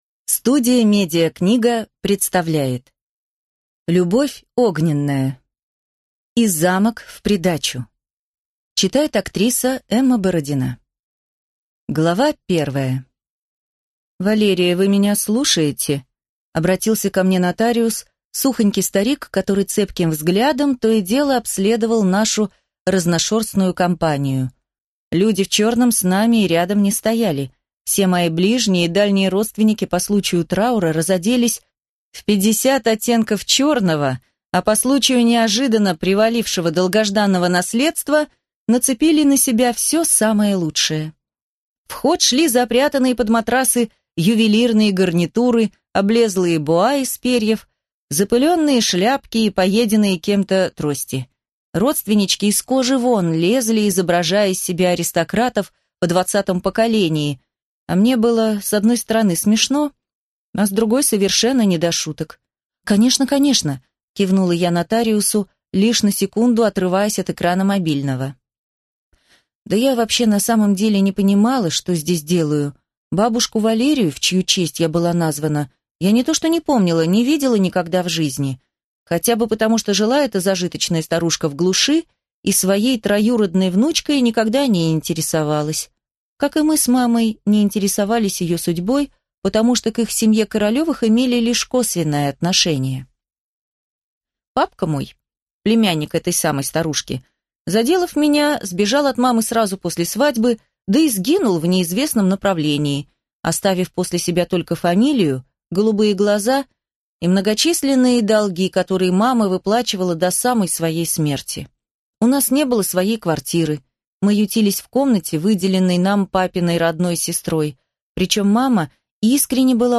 Аудиокнига И замок в придачу | Библиотека аудиокниг